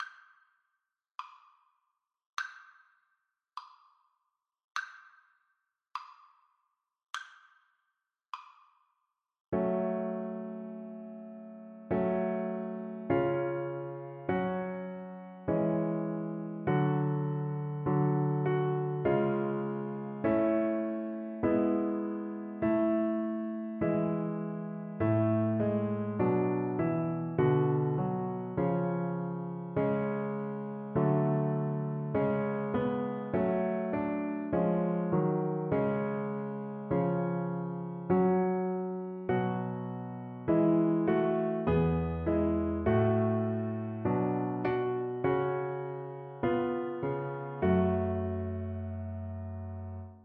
Christmas Christmas Violin Sheet Music What Star is This?
Violin
F# minor (Sounding Pitch) (View more F# minor Music for Violin )
Andante
2/4 (View more 2/4 Music)
Traditional (View more Traditional Violin Music)